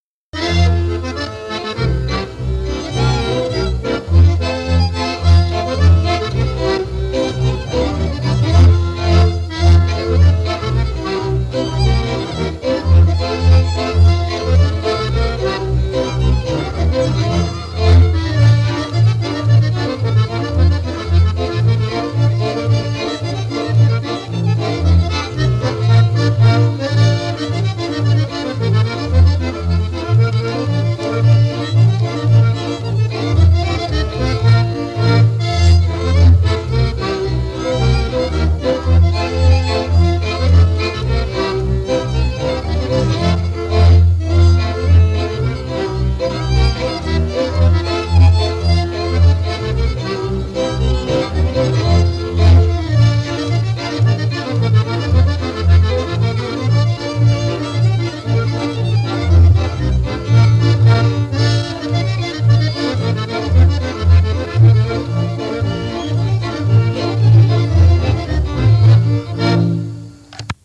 Instrumentalny 16 – Żeńska Kapela Ludowa Zagłębianki
Nagranie archiwalne